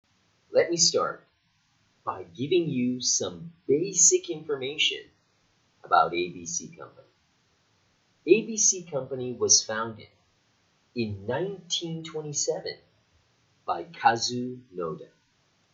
◆わかりやすい話し方の２つのテクニック
①意味のまとまりで話を止める赤い線
②重要な単語を強く発音する青い線
音声を聴き比べれば、テクニックを使った話す方が、断然聞き取りやすかったと思います。